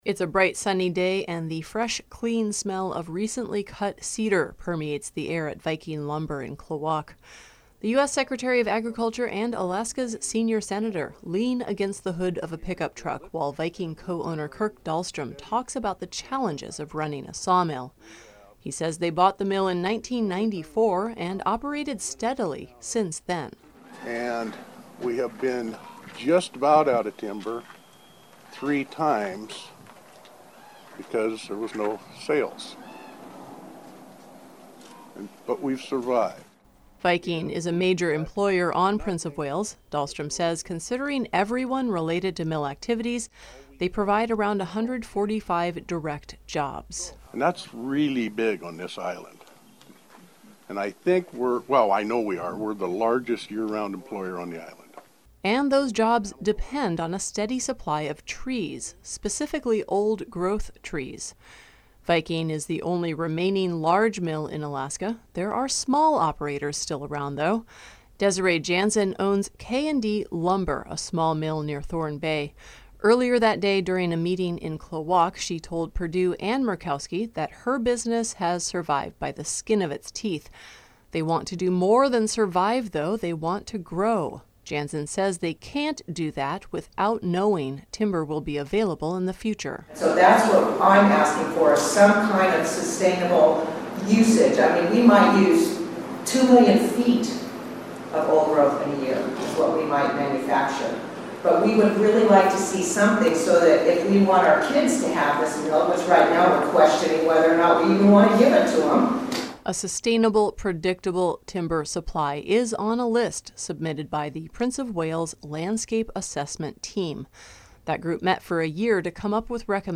It’s a bright, sunny day and the fresh, clean smell of recently cut cedar permeates the air at Viking Lumber in Klawock.